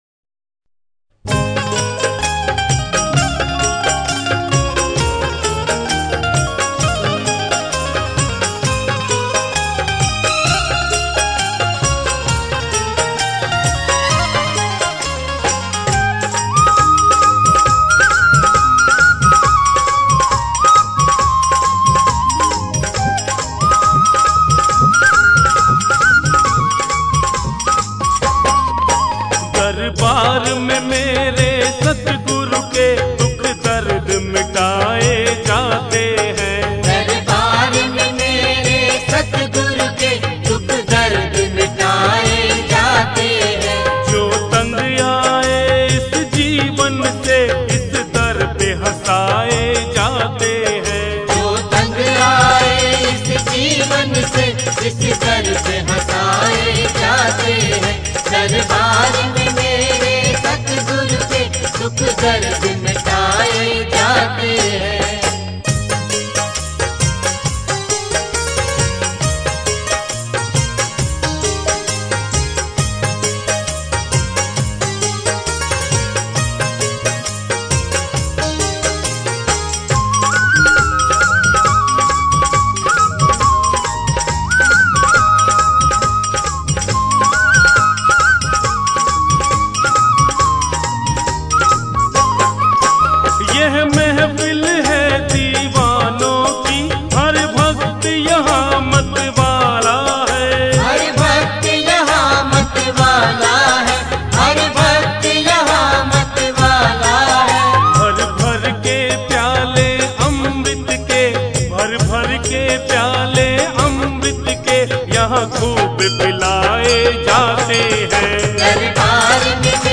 Bhajan